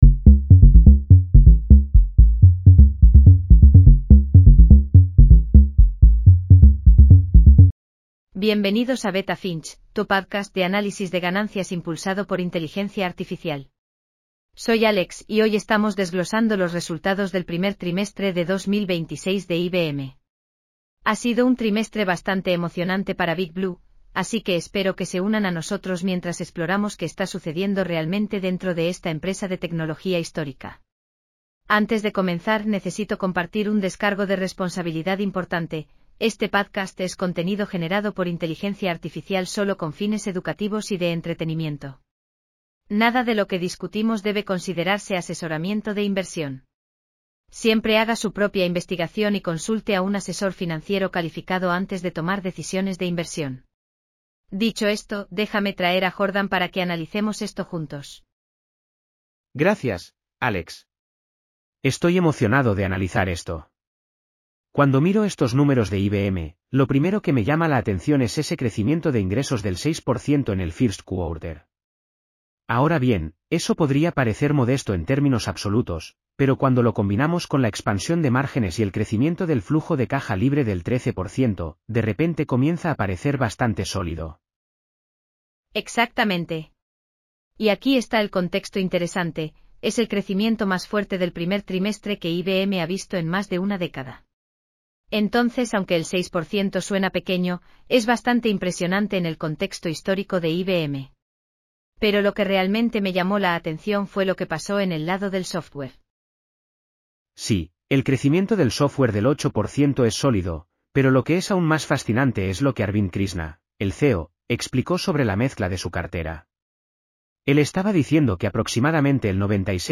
Bienvenidos a Beta Finch, tu podcast de análisis de ganancias impulsado por inteligencia artificial.